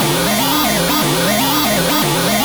TRASHY SEQ-R.wav